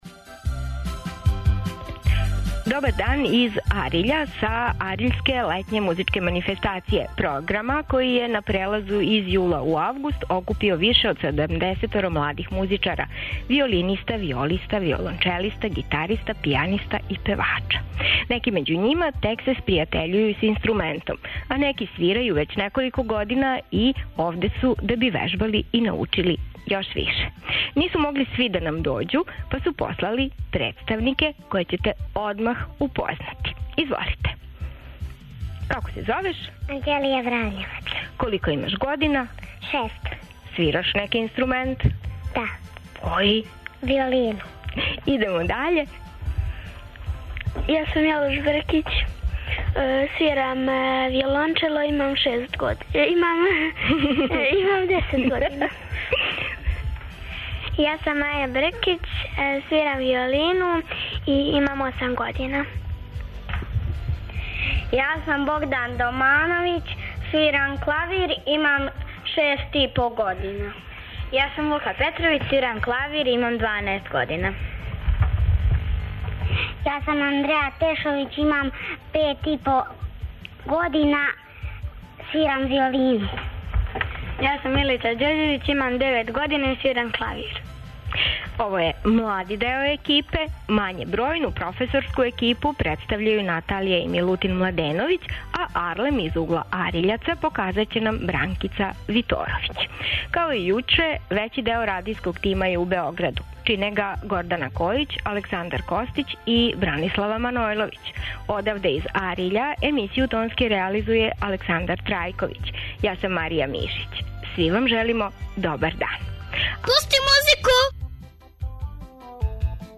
Емисија се реализује из Ариља, где се одржава АРЛЕММ - летња музичка школа за младе музичаре од 5 до 25 година. Саговорници су нам они најмлађи.